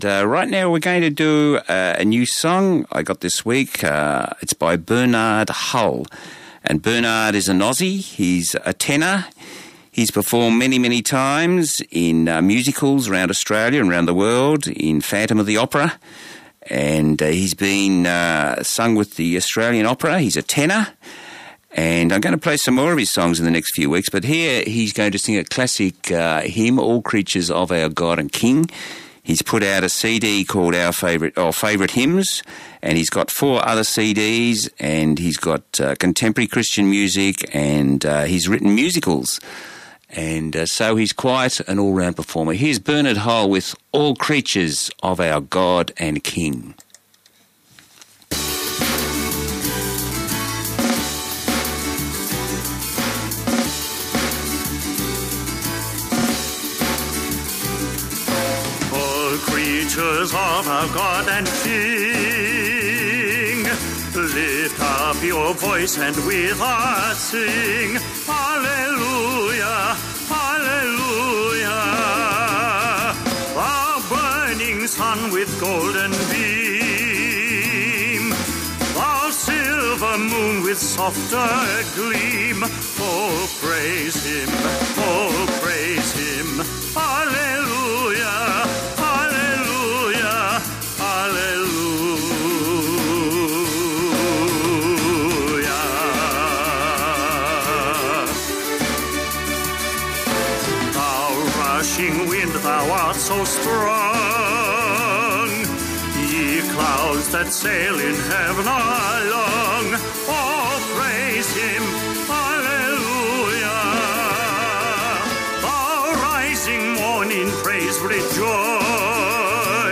traditional Christian hymn
Broadcast on Southern FM on 10 June 2012.